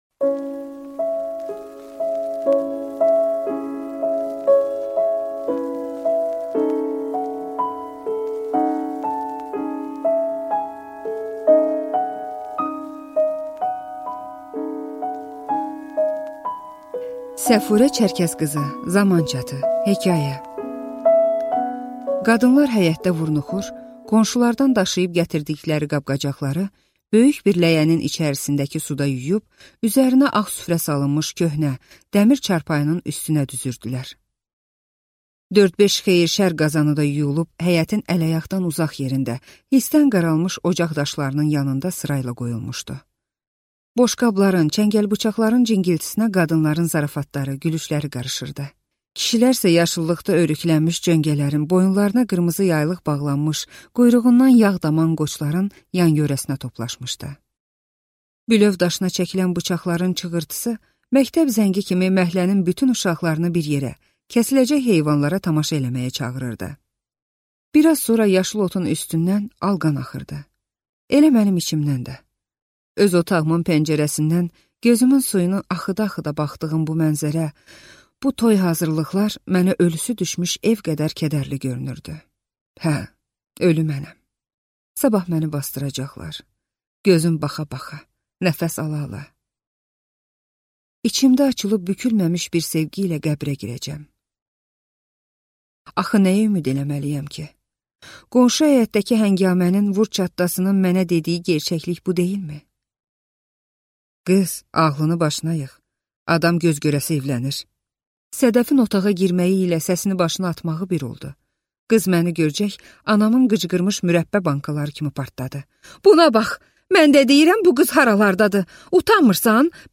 Аудиокнига Zaman çatı | Библиотека аудиокниг